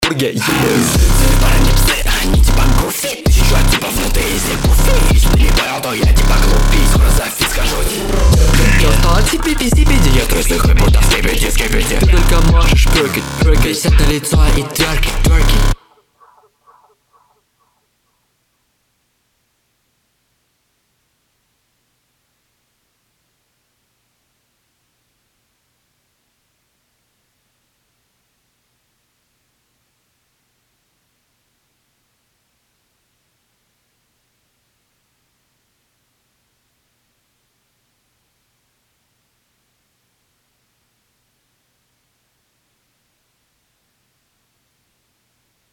Когда свожу вокал с инструменталом возникает следующая проблема - чуть чуть убавлю вокал, он проваливается окончательно в микс, чуть чуть поднимаю (диапазон - вниз и вверх около 2дб соотв.) - он сильно выпирает поверх микса.